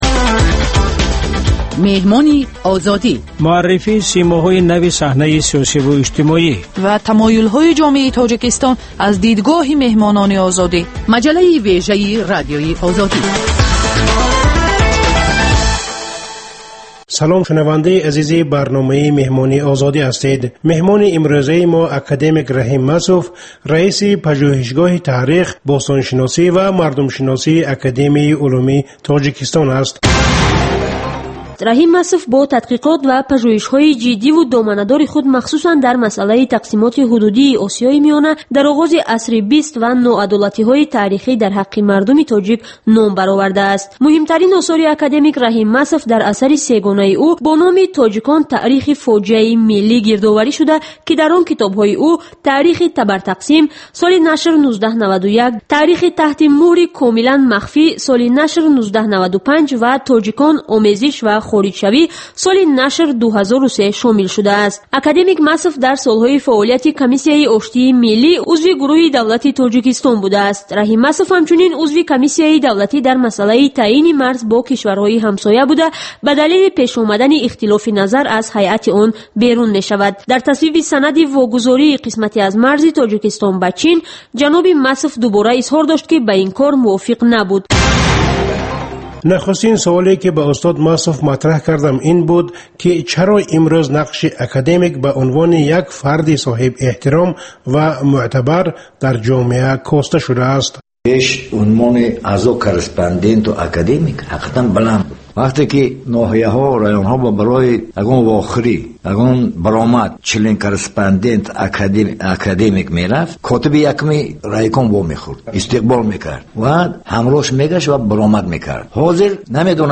Гуфтугӯи ошкоро бо чеҳраҳои саршинос, намояндагони риштаҳои гуногун бо пурсишҳои ғайриодӣ.